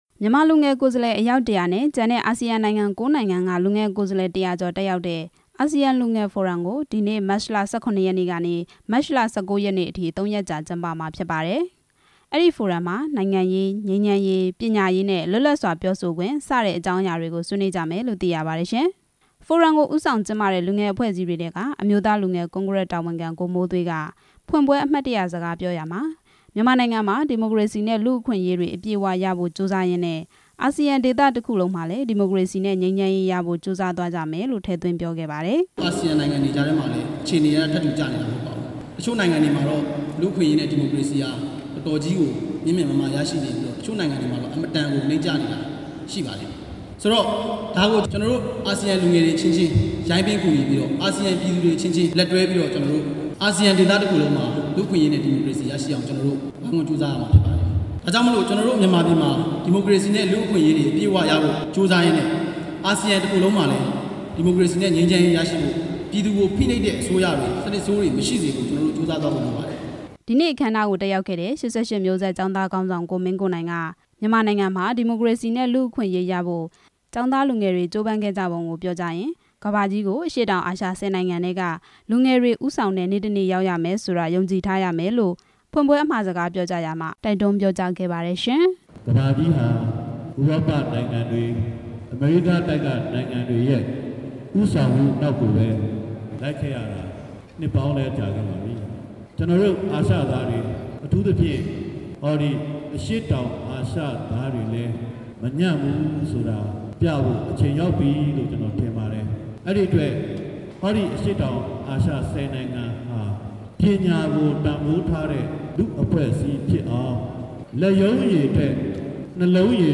ညီလာခံဖွင့်ပွဲအခမ်းနားကို တက်ရောက်လာတဲ့ မျိုးဆက်ငြိမ်းချမ်းရေးနဲ့ ပွင့်လင်း လူ့အဖွဲ့အစည်း ခေါင်းဆောင် ကိုမင်းကိုနိုင်က လူ့အခွင့်အရေး၊  ဒီမိုကရေစီအရေးနဲ့ ပညာကို တန်ဖိုးထားတဲ့ လူ့အဖွဲ့အစည်းတွေဖြစ်အောင် လူငယ်တွေ ကြိုးစားပေးကြဖို့  အခမ်းအနား မှာ ပြောကြားခဲ့ပါတယ်။